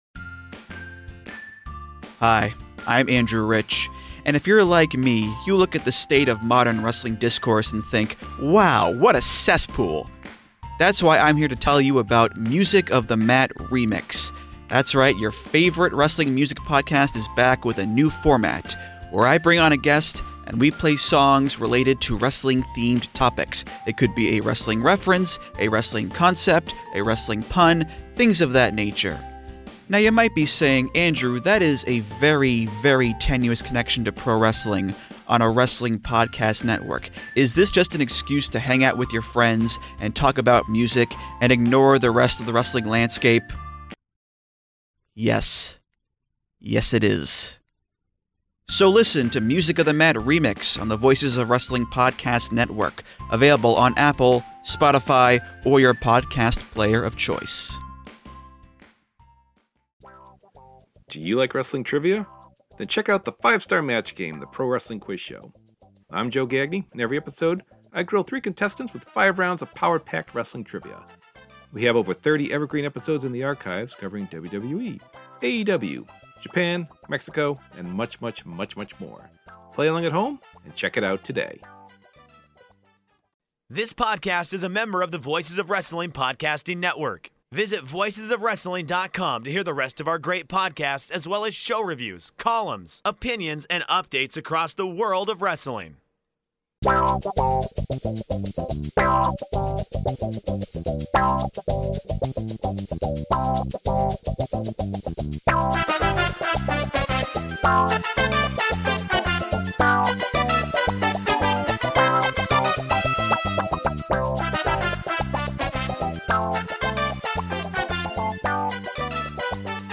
What happens when the body gets stuck in a loop it can’t break on its own — and how do we begin to listen to what the nervous system is asking for? In this conversation